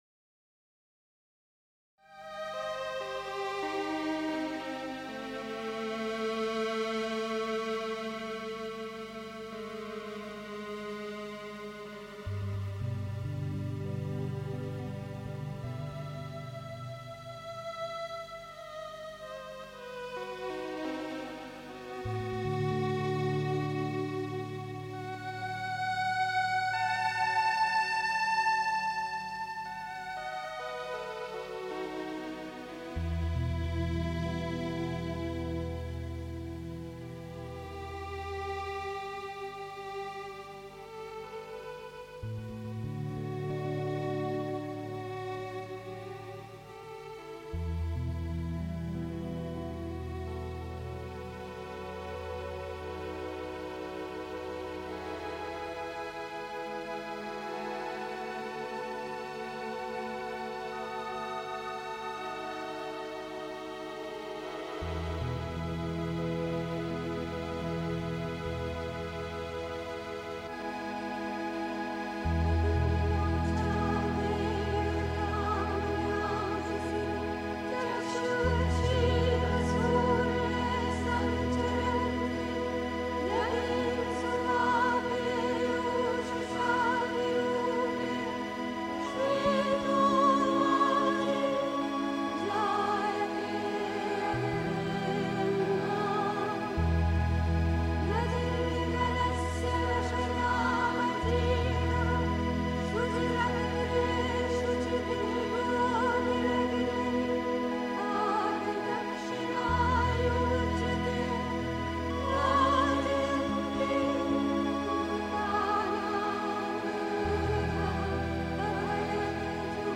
Eine dynamische Meditation (Sri Aurobindo, CWSA Vol. 23-24, pp. 112-23) 3. Zwölf Minuten Stille.